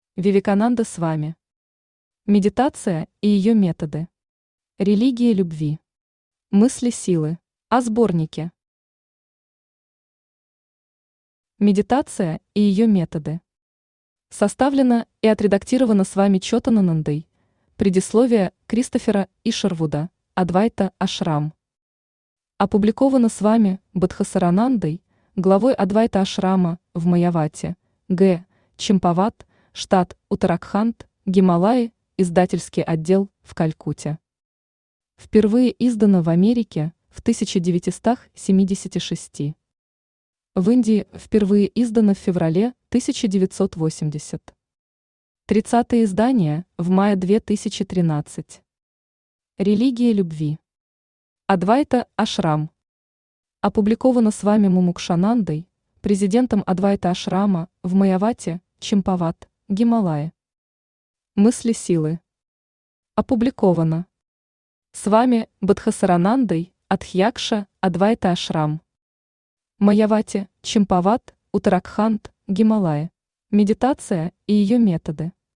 Аудиокнига Медитация и ее методы. Религия любви. Мысли силы | Библиотека аудиокниг
Мысли силы Автор Вивекананда Свами Читает аудиокнигу Авточтец ЛитРес.